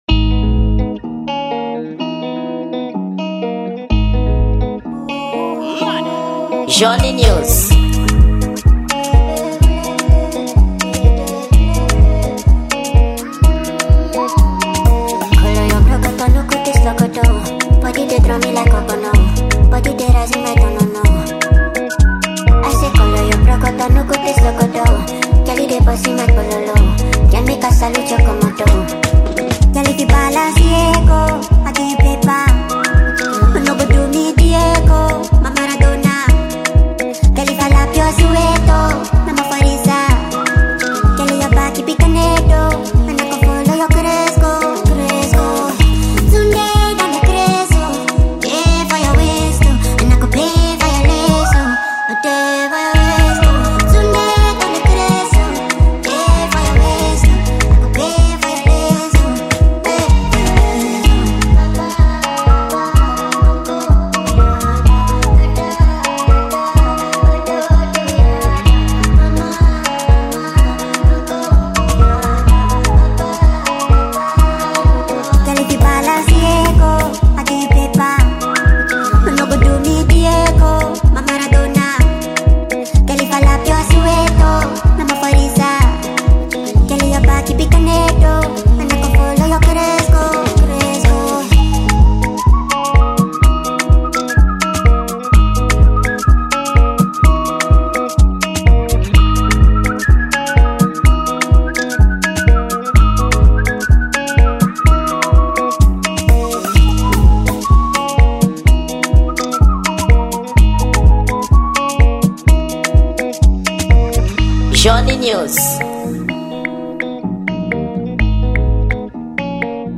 Gênero: Afro Beat